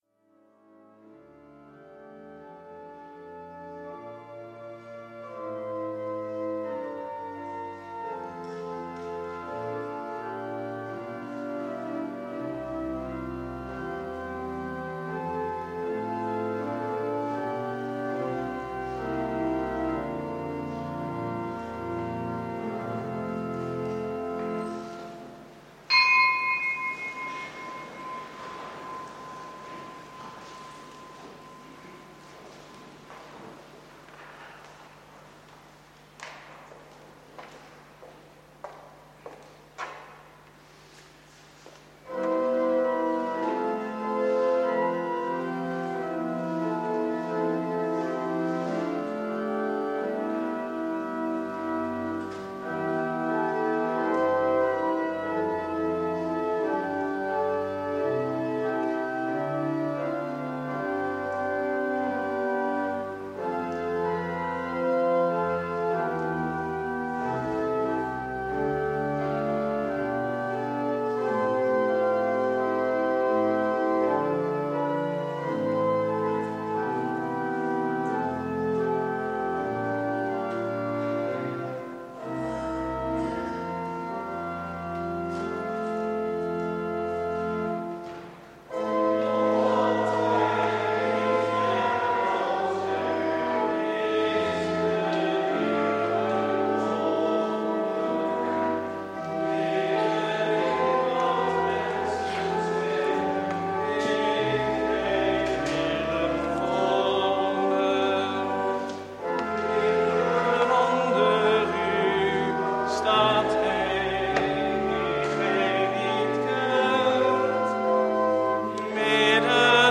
Eucharistieviering beluisteren vanuit de H. Willibrord te Oegstgeest (MP3)